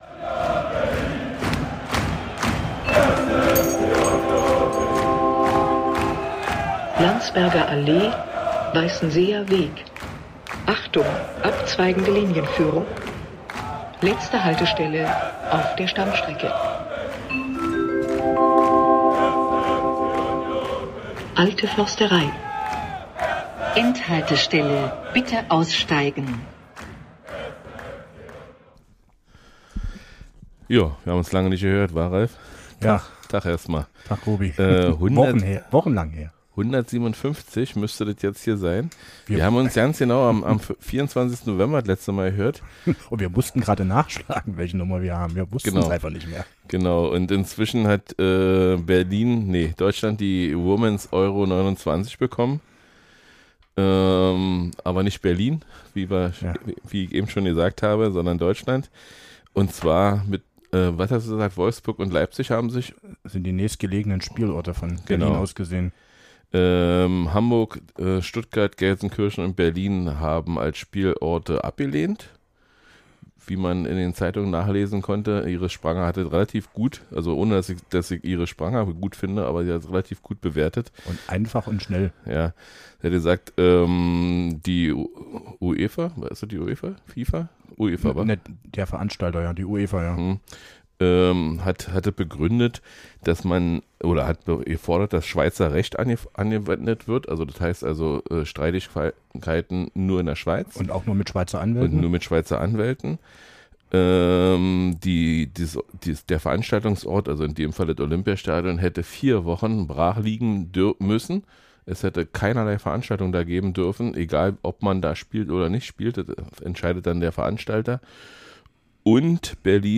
So konnten wir uns leider nur zu zweit unterhalten. Wir reden über die Bullen, über Hoffenheim, über Wolfsburg, Heidenheim, Bayern, das aktuelle Sportstudio und die Bullen.